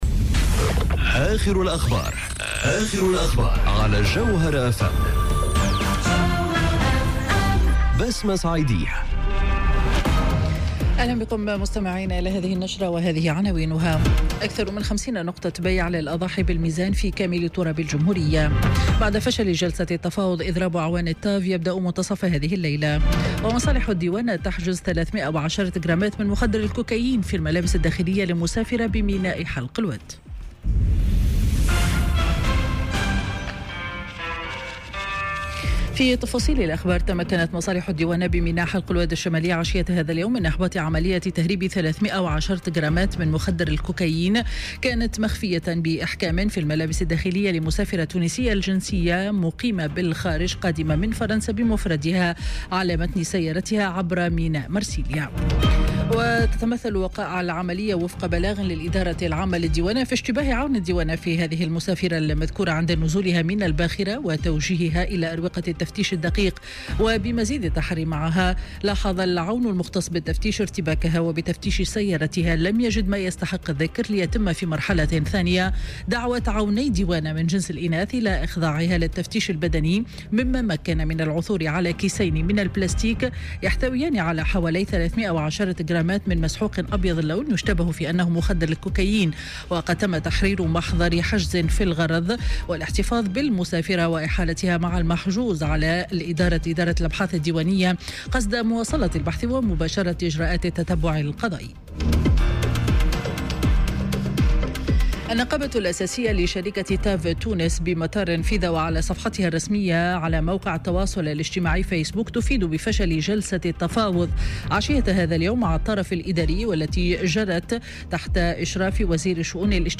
نشرة أخبار السابعة مساء ليوم الاثنين 6 أوت 2018